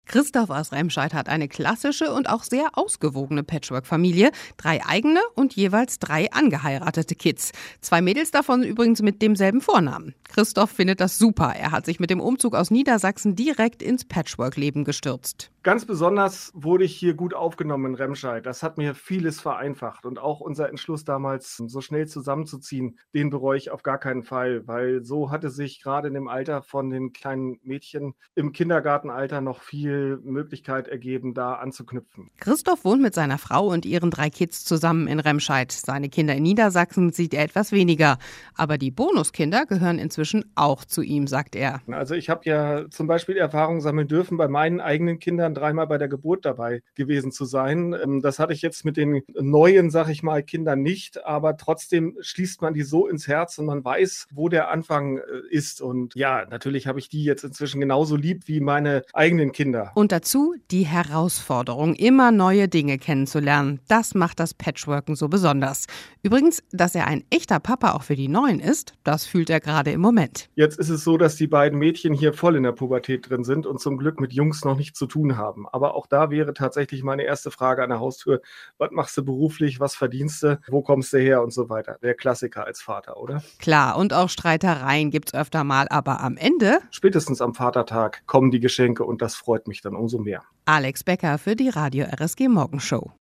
Mehrere RSG-Hörer haben uns in der Morgenshow von ihrem Familiengeschichten erzählt.